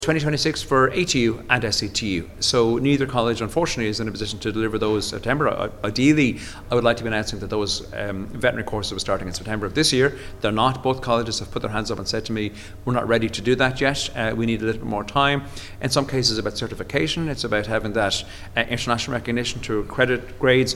Speaking at the Teachers' Union of Ireland's Congress, Minister Lawless says it's unfortunate the option is not available for students this year, but work is progressing.